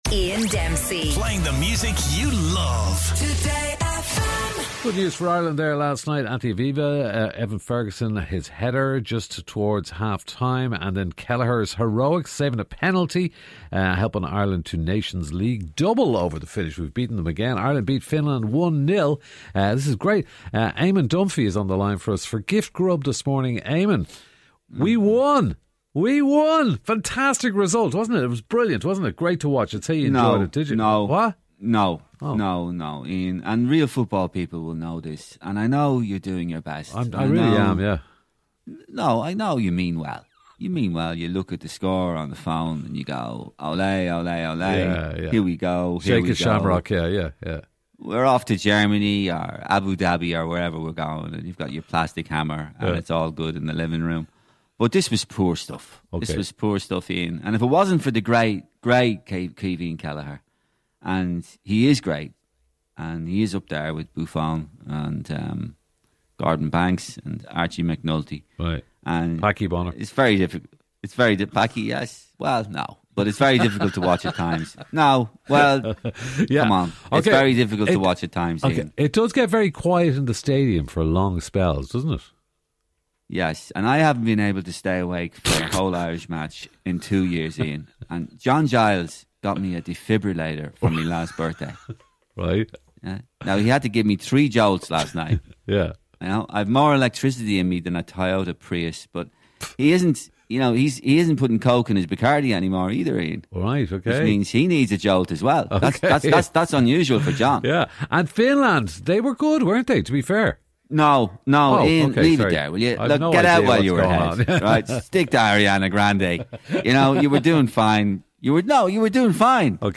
on the line this morning for a bit of a rant